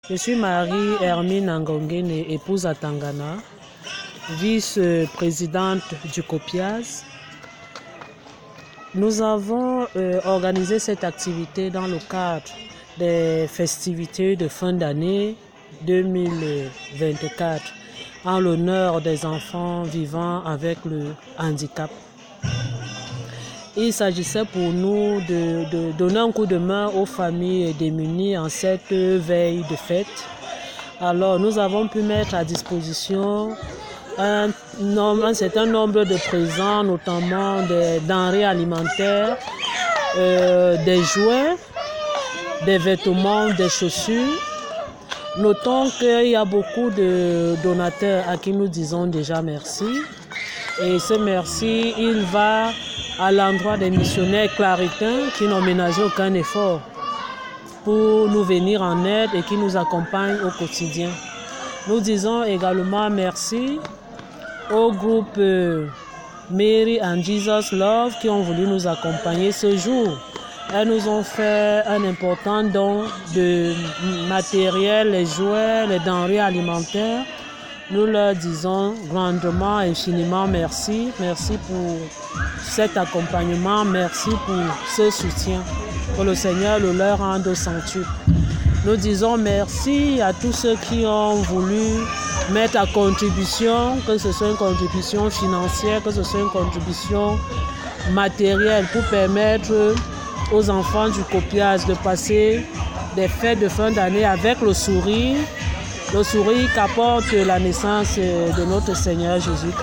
Cameroun- COPIASEH : Une cérémonie de l’Arbre de Noël dédiée aux enfants en situation de handicap